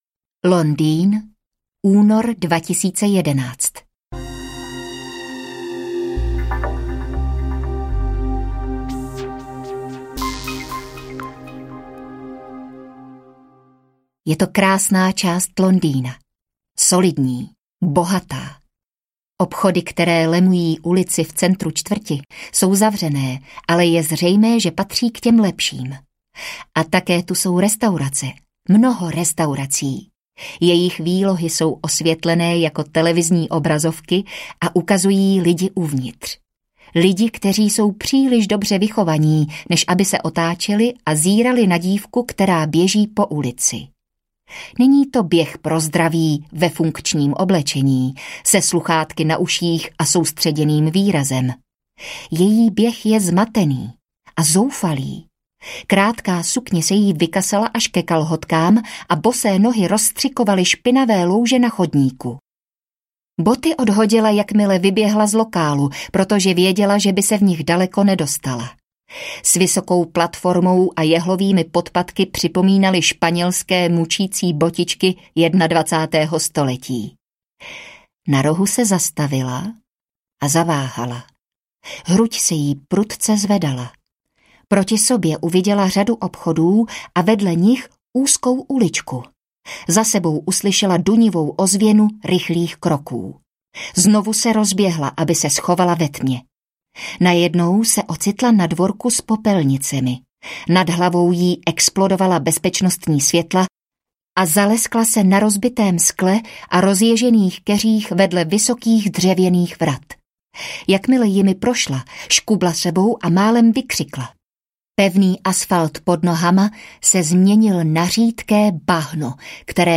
Dopisy, které nikdo nečetl audiokniha
Ukázka z knihy
• InterpretAndrea Elsnerová, Marek Holý